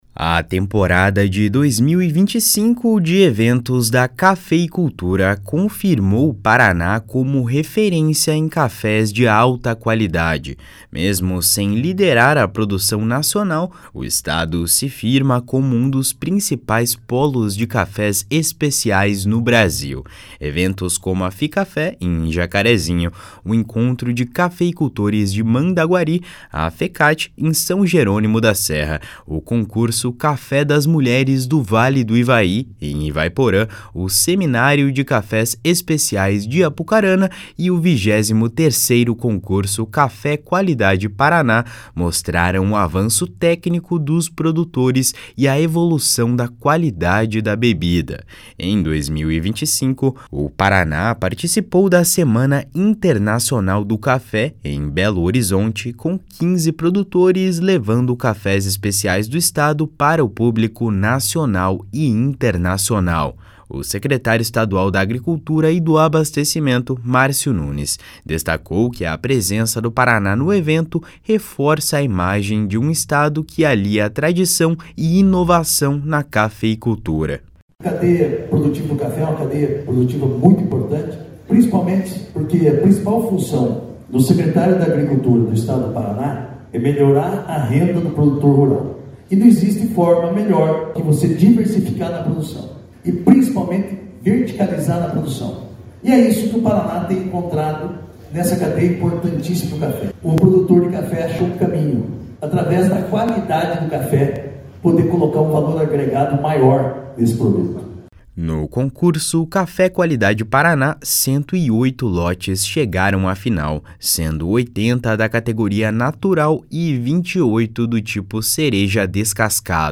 // SONORA MARCIO NUNES //